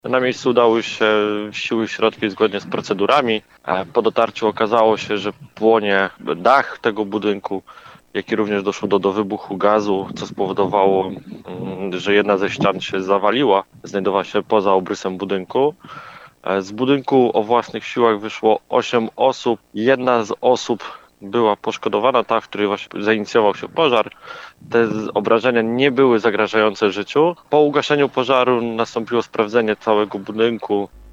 Wiadomości